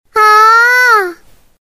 萝莉音不情愿啊一声音效免费音频素材下载